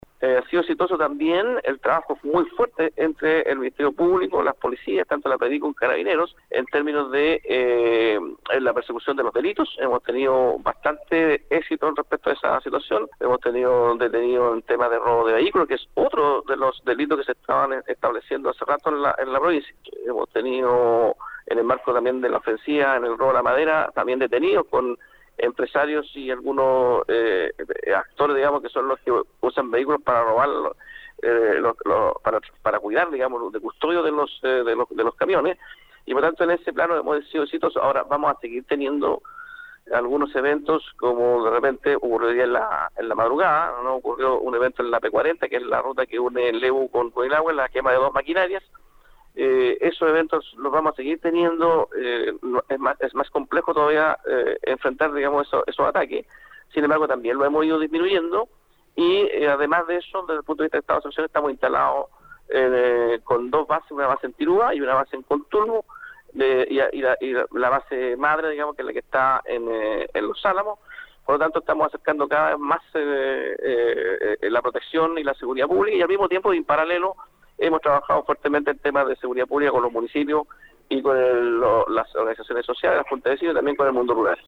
La autoridad provincial, en entrevista con Nuestra Pauta, destacó tanto el trabajo coordinado entre las policías y el Ministerio Público en la persecución de delitos como el vinculo con municipios y organizaciones sociales en materias de seguridad pública.